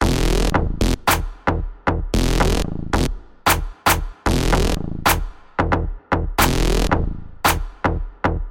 描述：一个小的恍惚的和弦进展
标签： 113 bpm Trance Loops Synth Loops 1.43 MB wav Key : Unknown
声道立体声